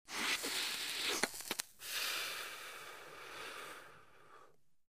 Звуки сигарет
Шум выдоха дыма после затяжки